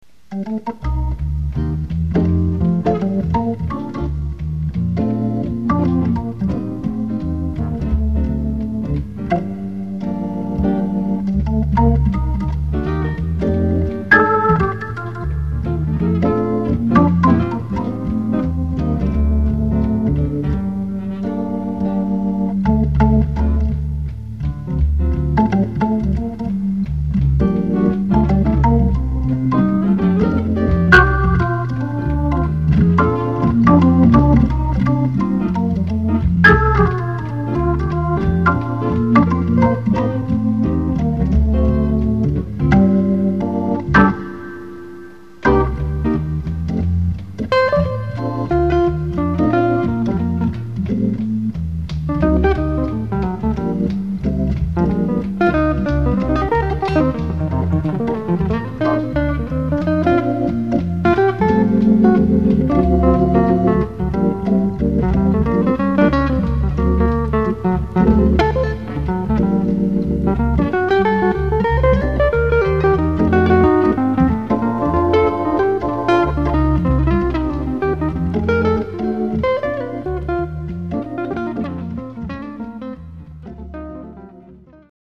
Guitar/Organ Duo